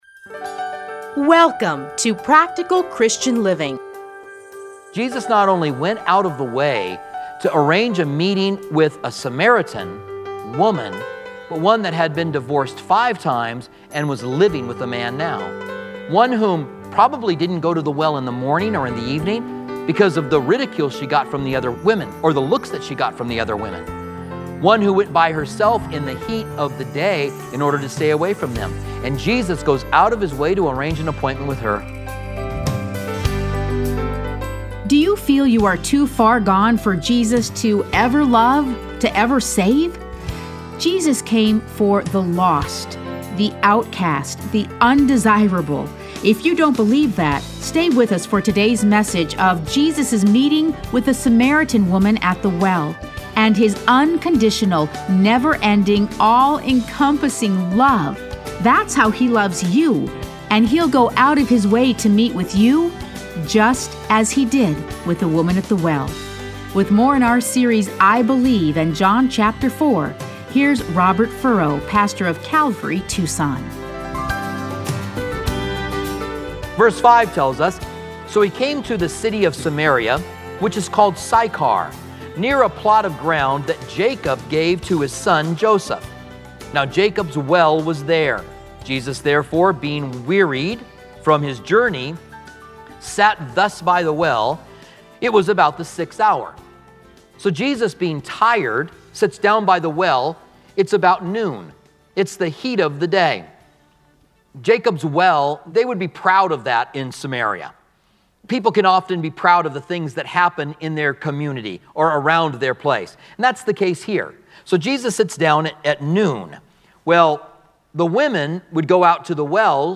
Listen to a teaching from John 4:1-29.